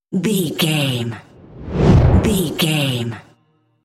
Whoosh deep fast
Sound Effects
Fast
dark
intense
whoosh
sci fi